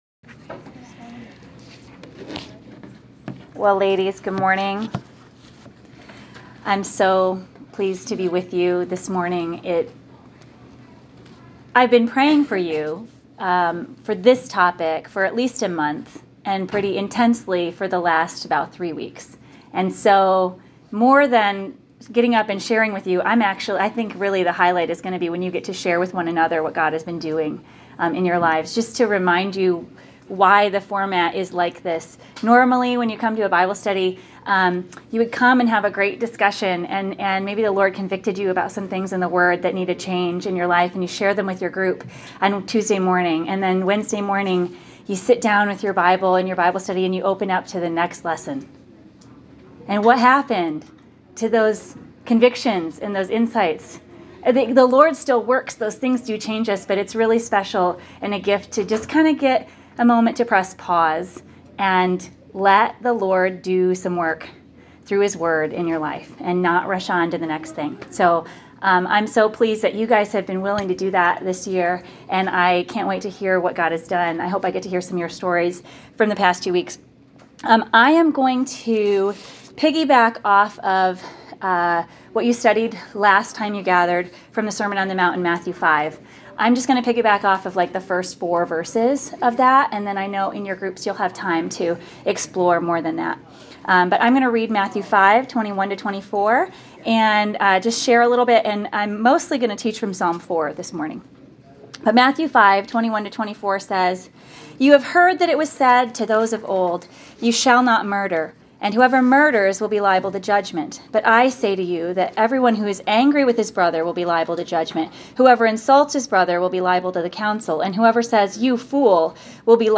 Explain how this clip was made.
The following talk was recorded November 5th, 2019 at Grace Church Eden Prairie. The main Scripture texts for this talk are Matthew 5:21-24 and Psalm 4.